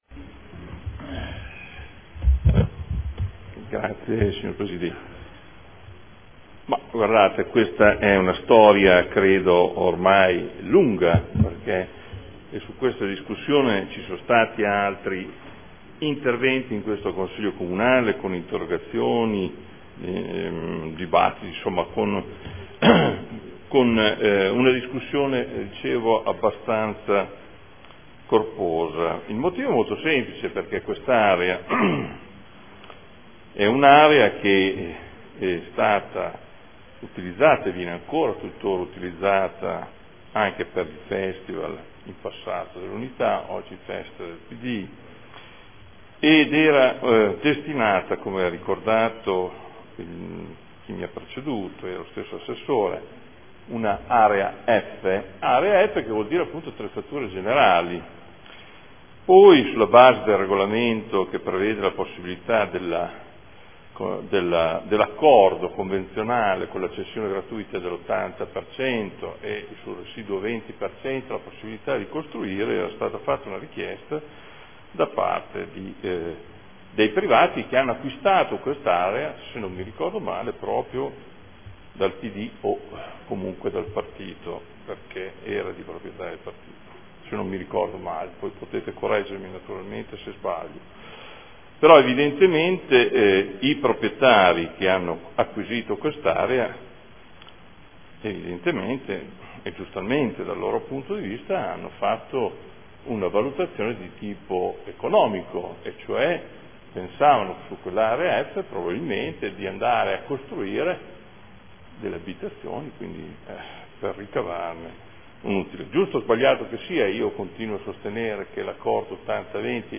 Seduta del 20/03/2014 Variante al Piano Strutturale Comunale (PSC) – Area ubicata tra Tangenziale, Strada Ponte Alto e Stradello Anesino – Zona elementare 2050 – Area 01